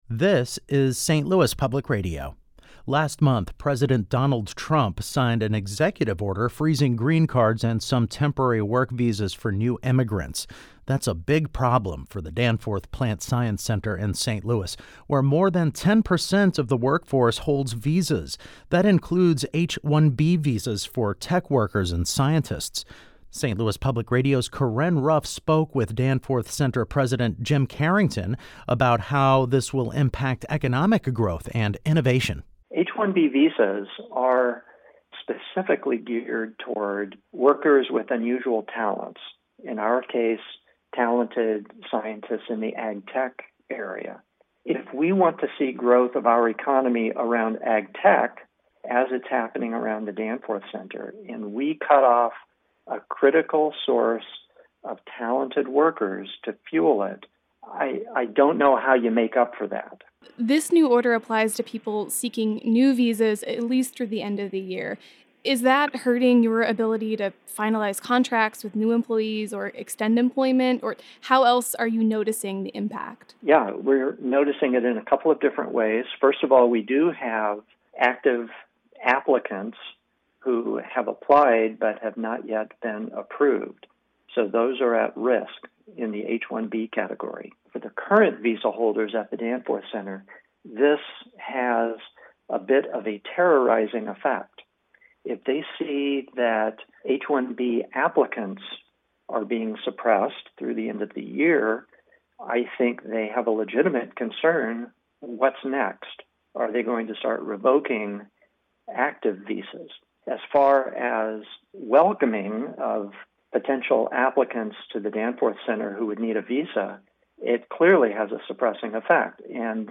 This interview was edited and condensed for clarity.